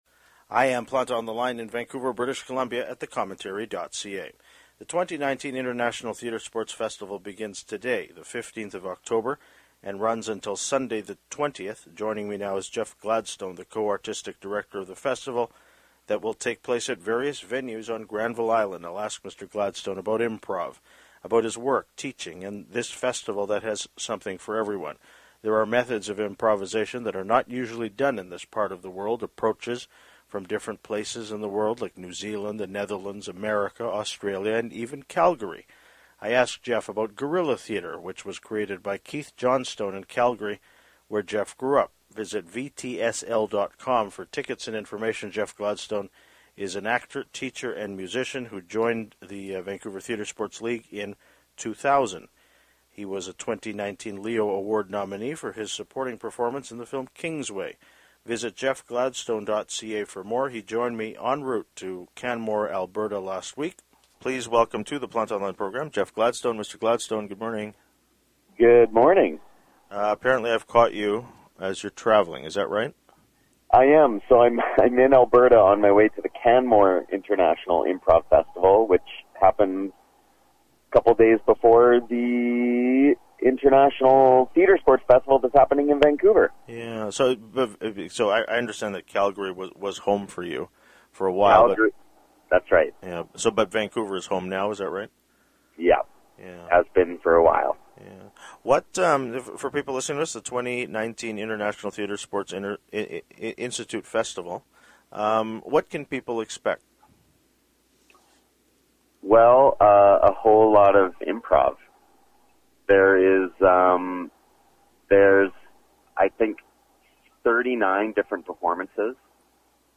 He joined me en route to Canmore, Alberta last week.